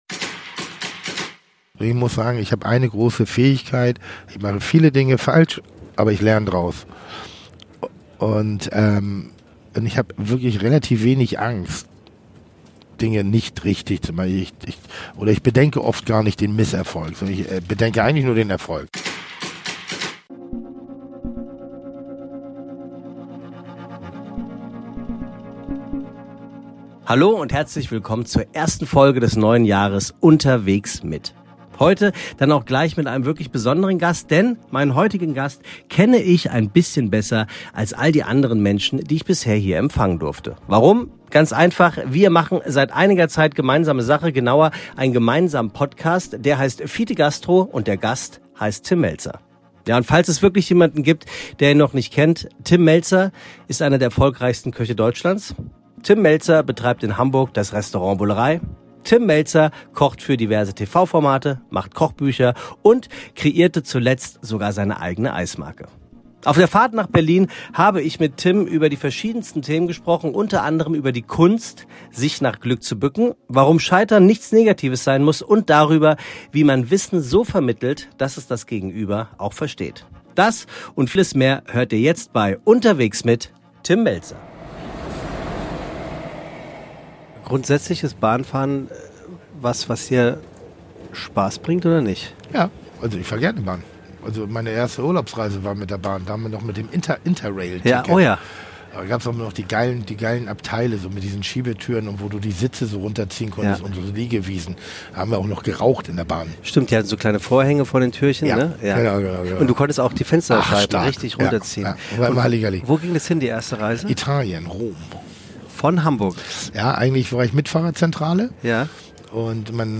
Außerdem: warum Tim den Begriff „Scheitern“ nicht mag, wie er es schafft, das Glück an sich zu binden und warum Loyalität und Ehrlichkeit bei ihm ganz oben auf der Liste der Tugenden stehen. Ein lebhaftes Gespräch über die Notwendigkeit des Grenzensetzens, Wertschätzung sowie Gladiolen und Cockerspaniel.